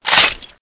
assets/pc/nzp/sounds/weapons/browning/boltforward.wav at 6d305bdbde965e83d143ab8cd4841a6c7b68160c
boltforward.wav